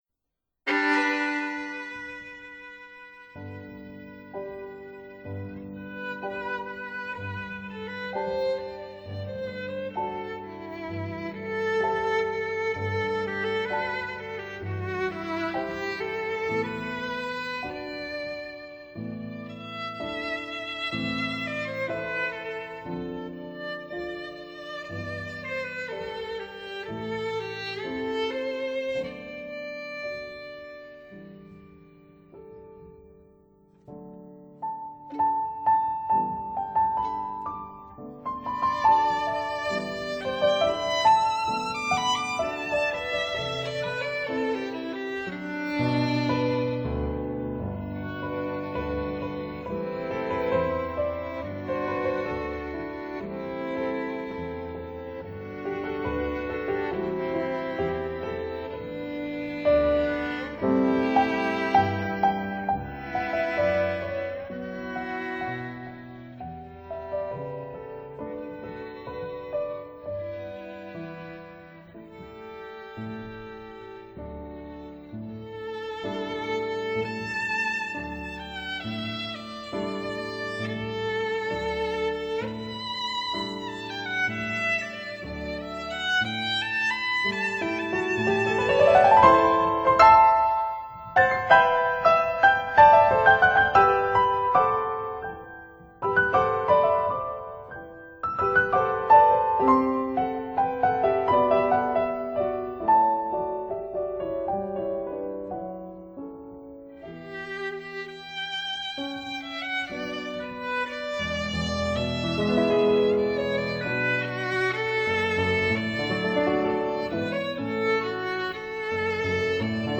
•(01-03) Trio for Clarinet, Cello and Piano
•(04-06) Sonata for Viola and Piano
•(07-09) Trio for Flute, Violin and Piano
•(10-12) Sonata for Violin and Piano
flute
clarinet
violin & viola
cello
piano